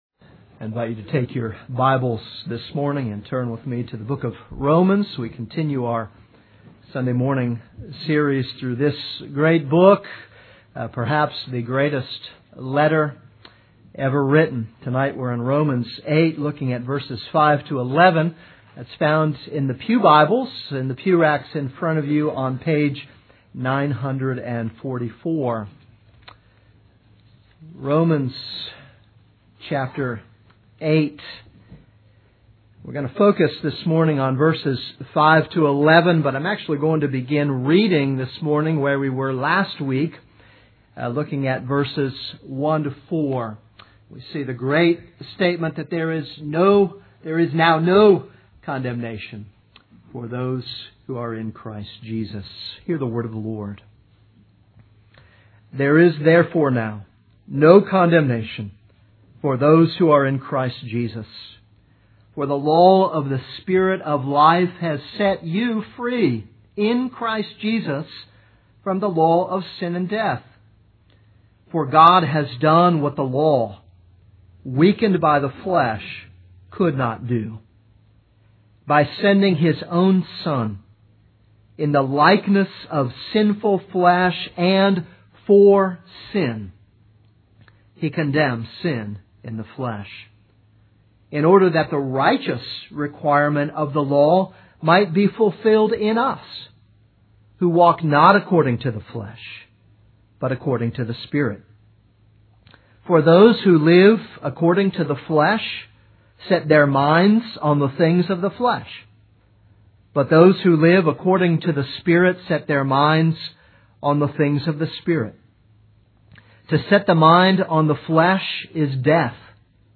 This is a sermon on Romans 8:5-11.